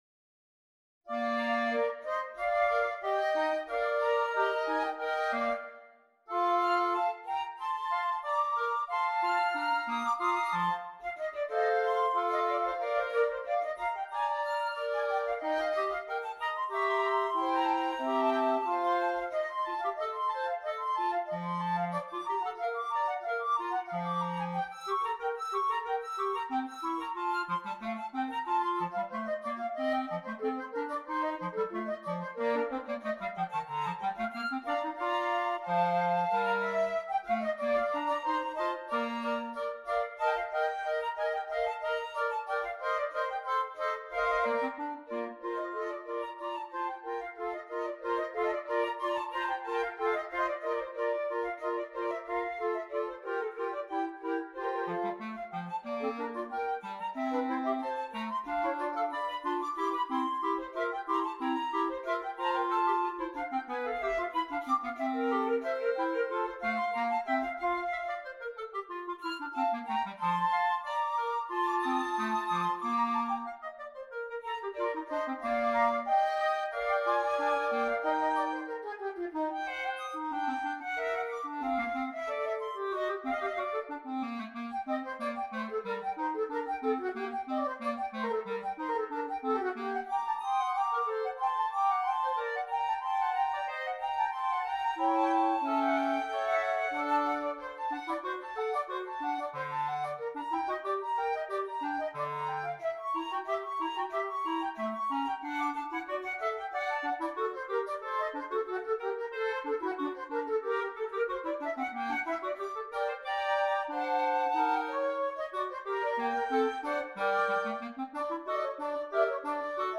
Gattung: Für 2 Flöten und 2 Klarinetten
Besetzung: Ensemblemusik für 4 Holzbläser